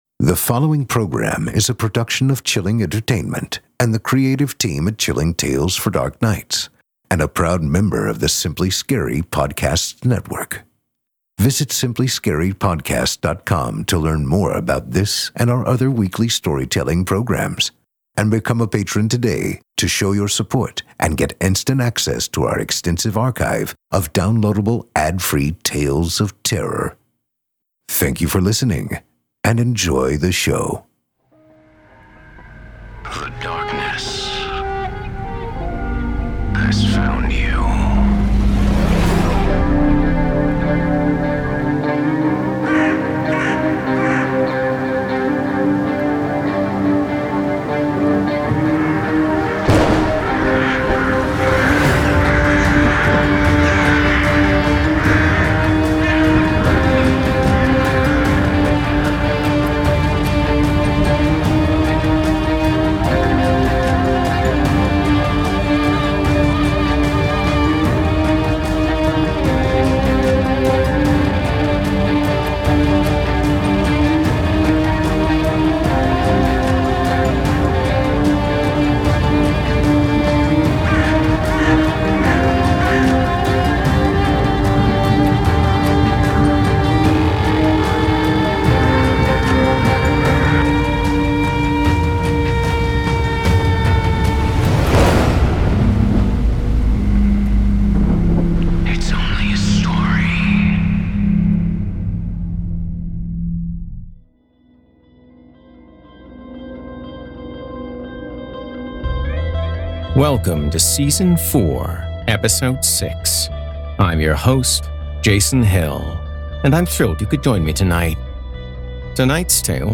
a terrifying feature-length tale